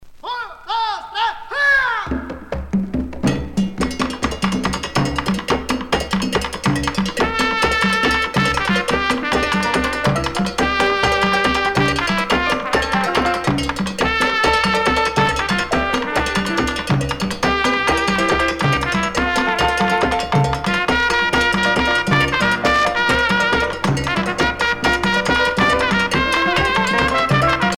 danse : conga
Pièce musicale éditée